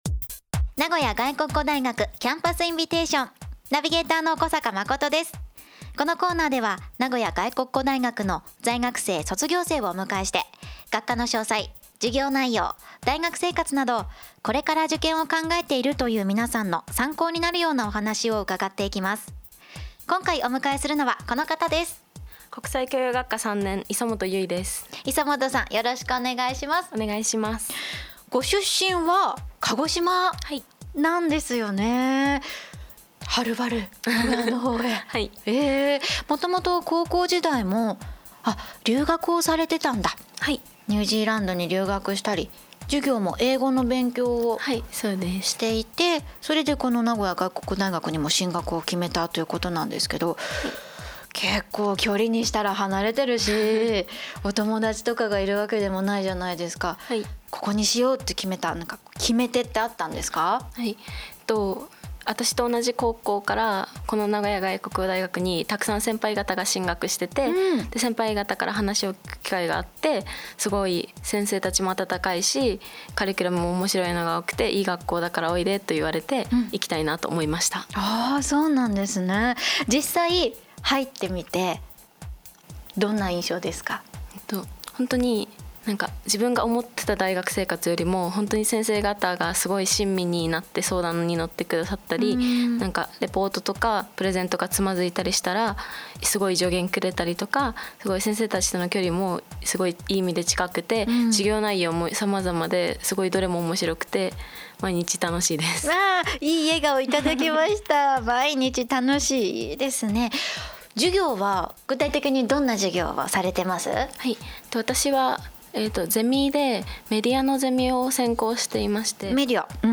名古屋外国語大学の在学生や卒業生をお迎えして、